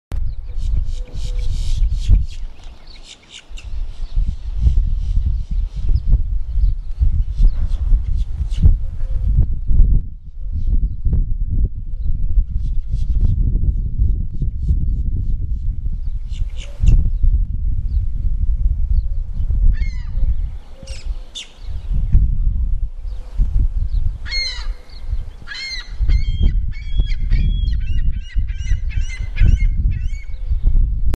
Low Tide Sounds
low-tide-water-fowl-garden-city-22214.mp3